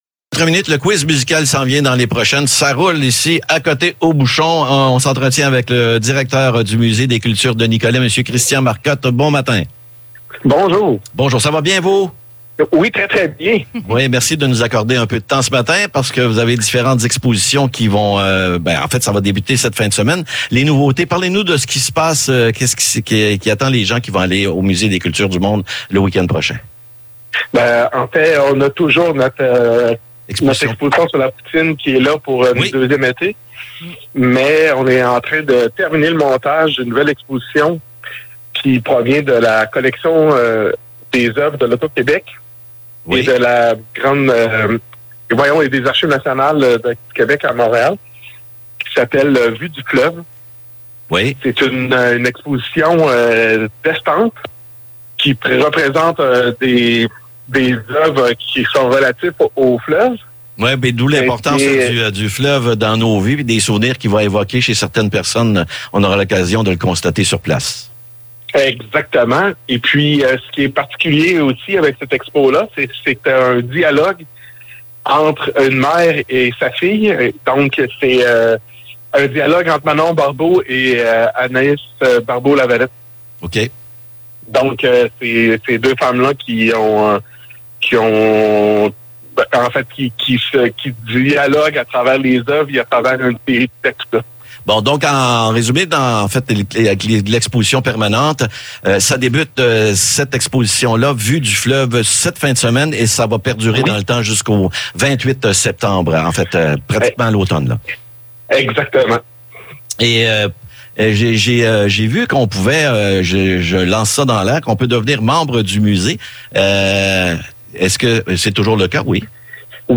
Chronique